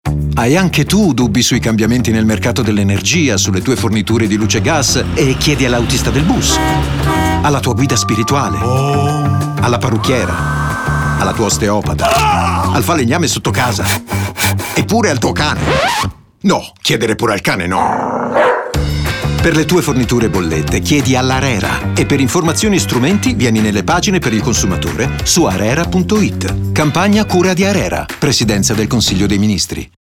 Lo spot radio
arera-radio-30.mp3